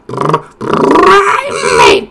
infinitefusion-e18/Audio/SE/Cries/PRIMEAPE.mp3 at releases-April